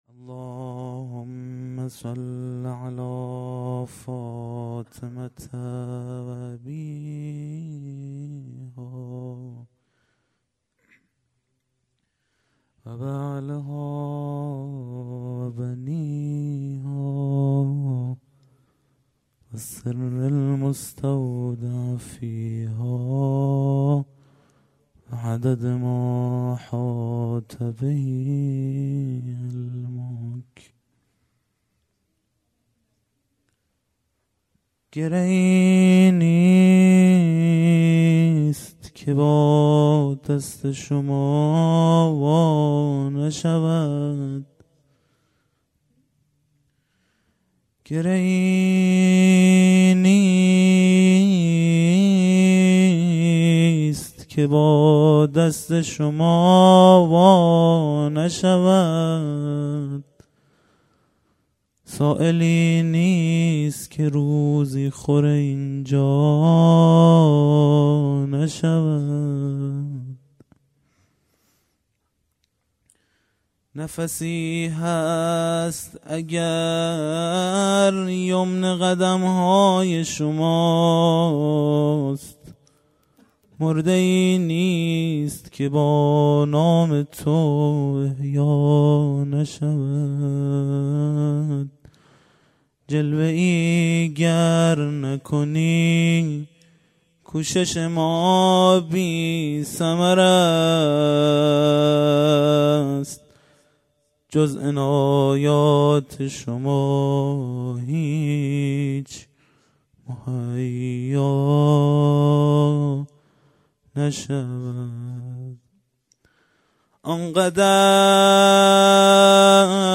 قرائت زیارت حضرت زهرا سلام الله علیها